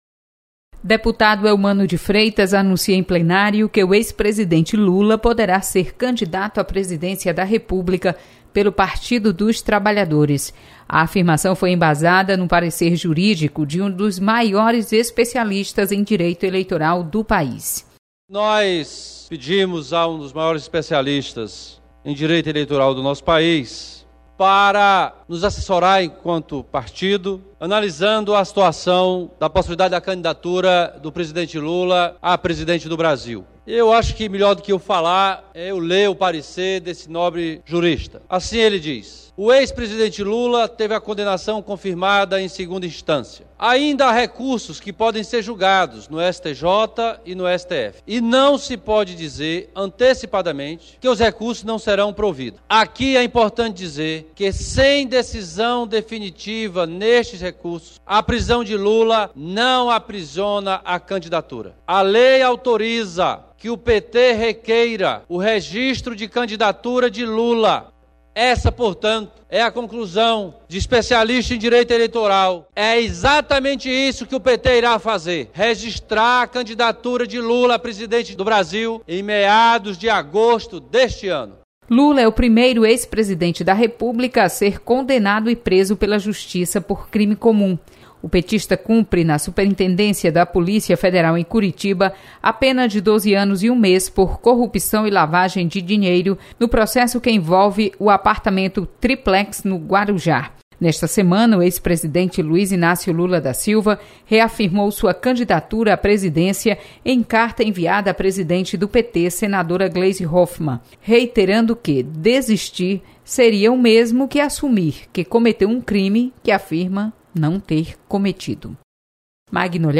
Deputado Elmano de Freitas destaca parecer sobre a candidatura de Lula à Presidência. Repórter